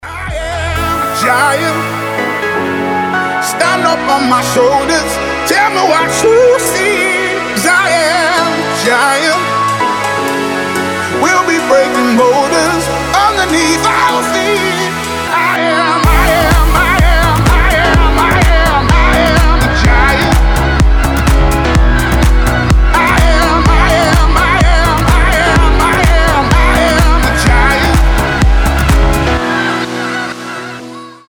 • Качество: 320, Stereo
ритмичные
remix
красивый мужской голос
EDM
пианино
house